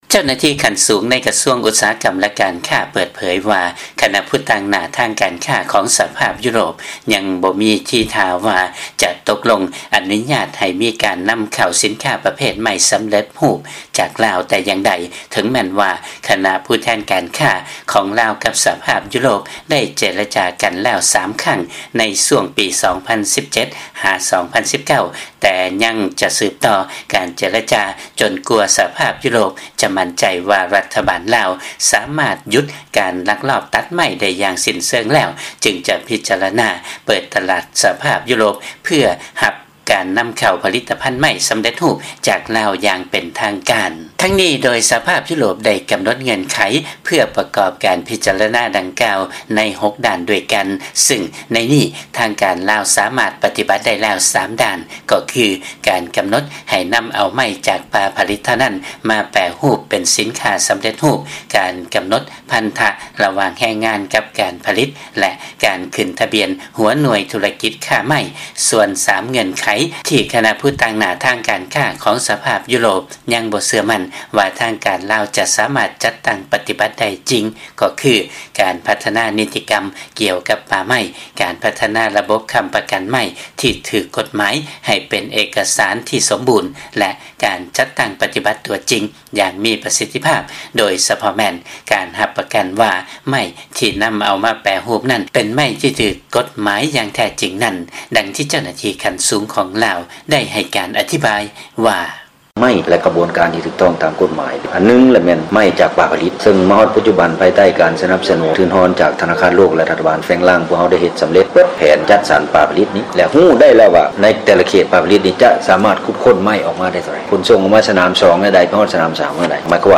ຟັງລາຍງານ ສະຫະພາບ ຢູໂຣບ ຍັງບໍ່ເຊື່ອໝັ້ນ ໃນມາດຕະການ ປາບປາມ ການລັກລອບຕັດໄມ້ ແລະ ການຄ້າໄມ້ເຖື່ອນໃນ ລາວ